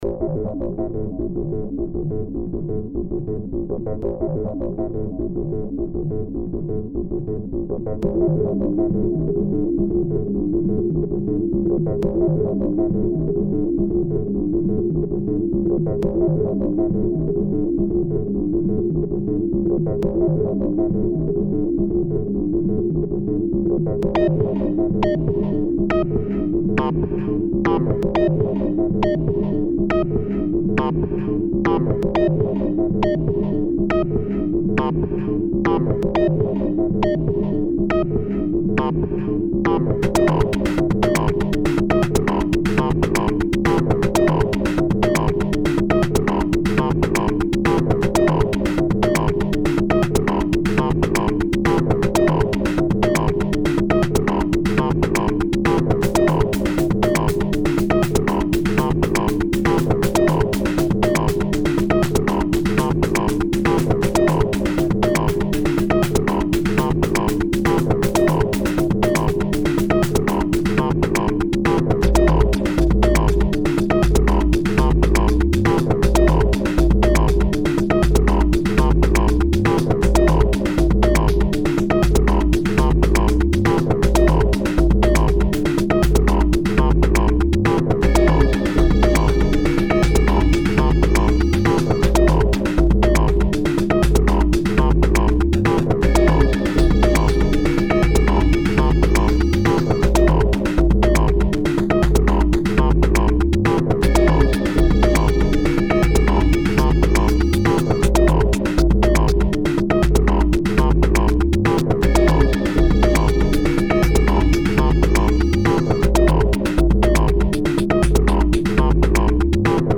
Genre Experimental